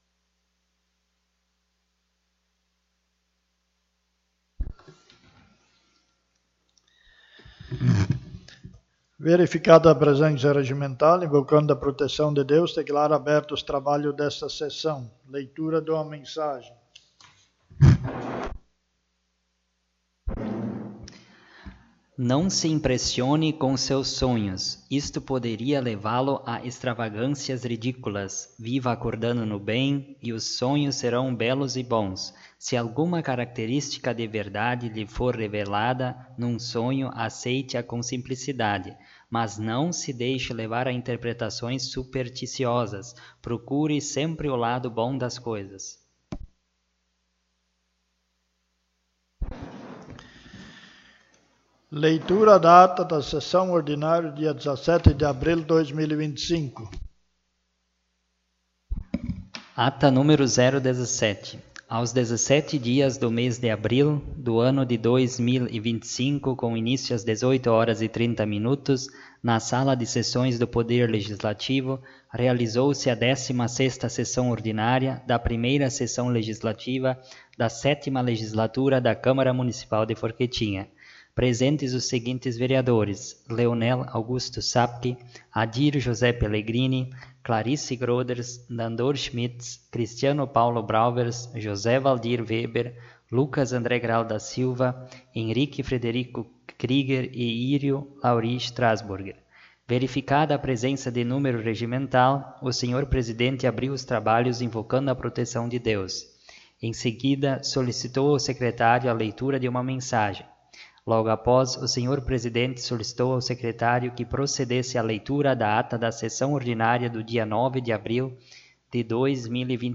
17ª Sessão Ordinária
Câmara de Vereadores de Forquetinha